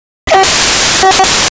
The English Horn Section Is Ruled By The Standard 2 Tone
Class 66 Horn Ref
class66horn.wav